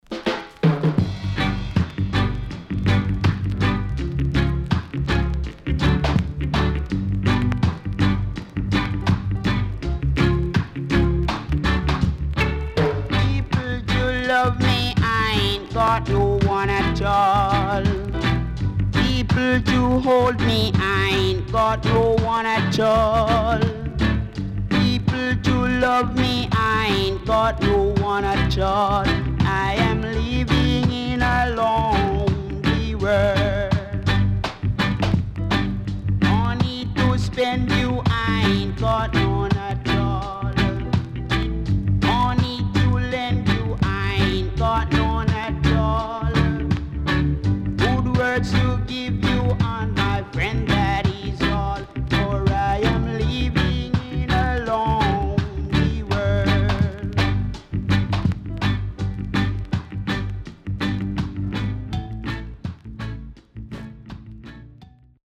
Rare.W-Side Great Rocksteady
SIDE A:全体的にチリノイズがあり、少しプチパチノイズ入ります。